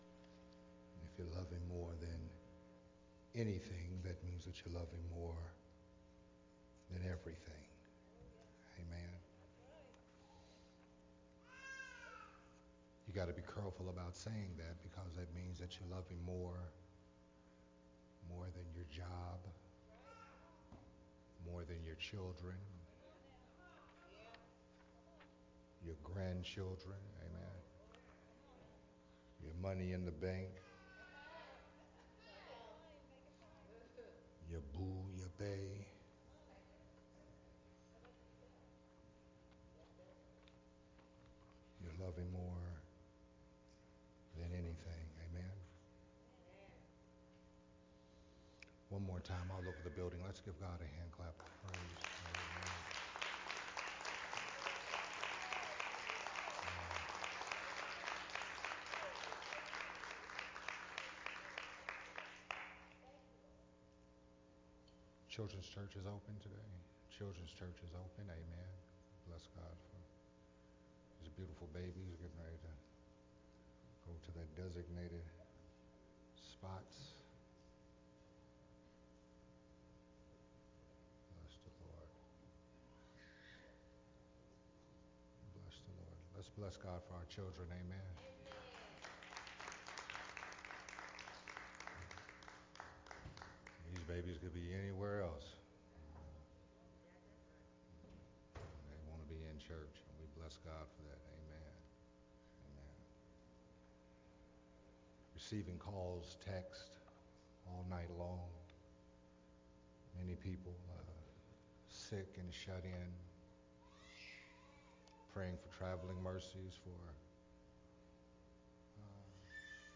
Recorded at Unity Worship Center on August 15th, 2021.